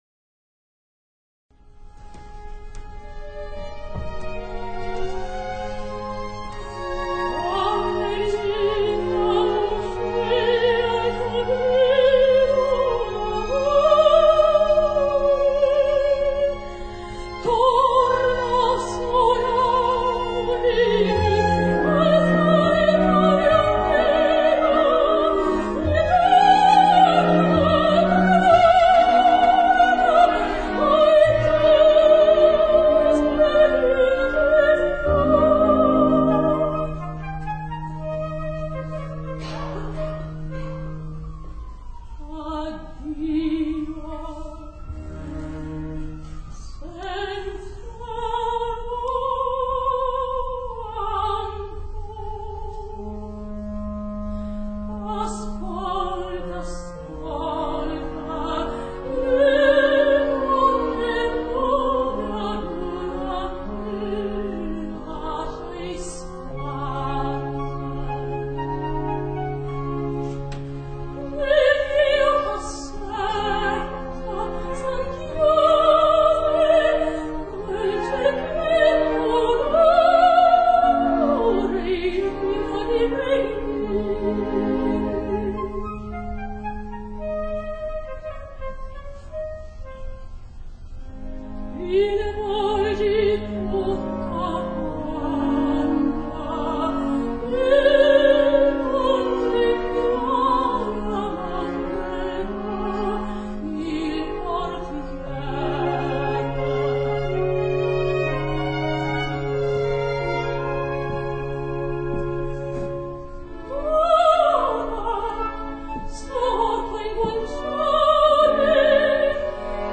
To hear her recent performance of "Donde Lieta" from La Bohème, choose below:
Opera